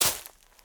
decorative-grass-02.ogg